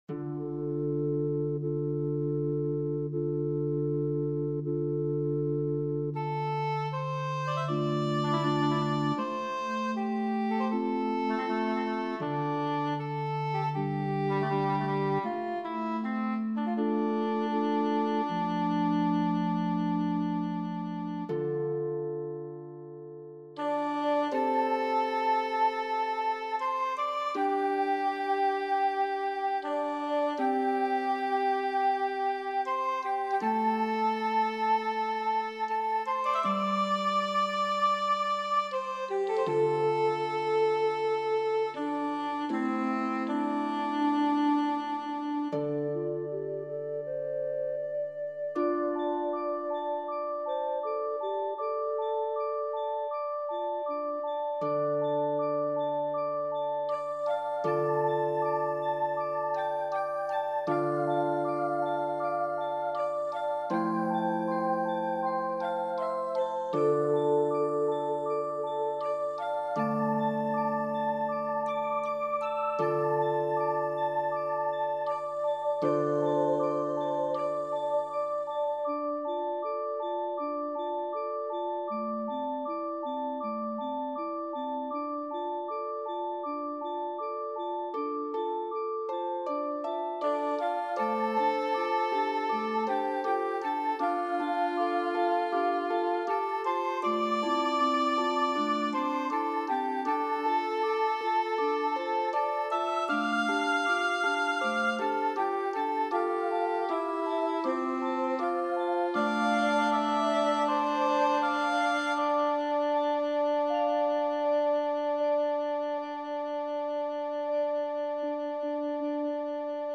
链接：乐曲）：